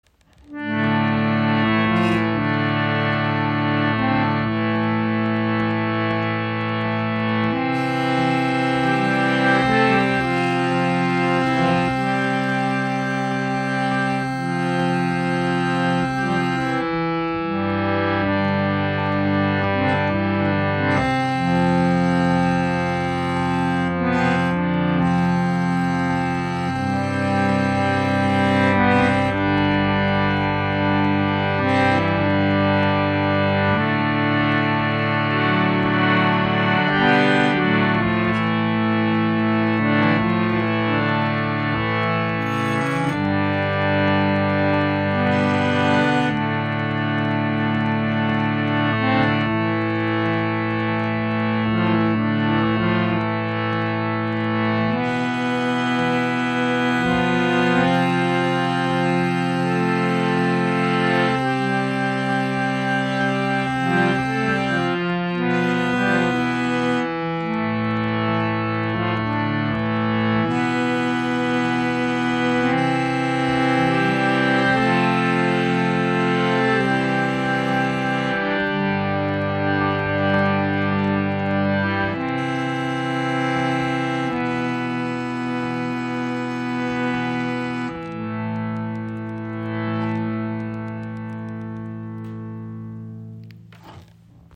Radha Harmonium | 2¾ Oktaven | Zedernholz Edition | 440 Hz
• Icon 2¾ Oktaven Klangumfang mit warmer Klangfarbe
Mit einer Klangbreite von 2¾ Oktaven und einer warmen, ausgewogenen Klangfarbe entfaltet es eine erstaunliche Tiefe. Jeder Ton ist mit je einer Zunge im mittleren und im tieferen Register ausgestattet, wodurch ein voller, tragender Klang entsteht.
Sein Klang ist warm und sanft, lässt sich sowohl sehr leise als auch kräftig spielen.
Die speziell eingestellten Federn sorgen für einen weichen, lang anhaltenden Klang – ohne störende Pumpgeräusche.